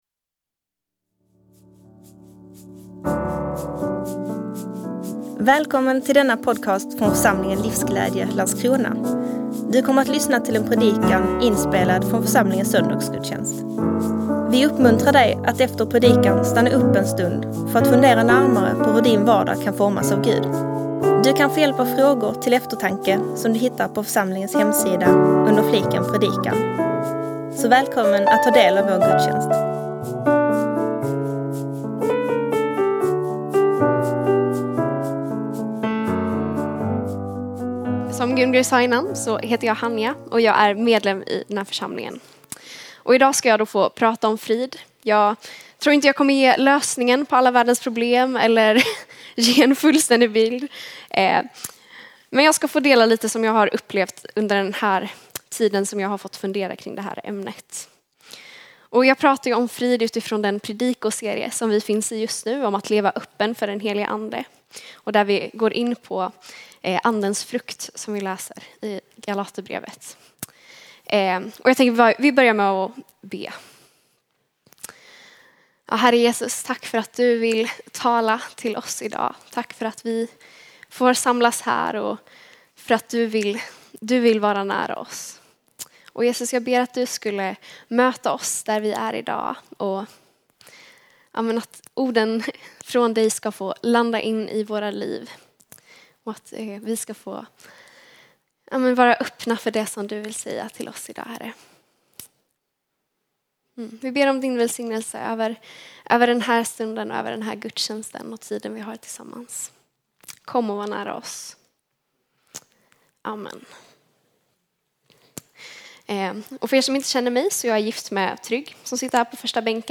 Predikant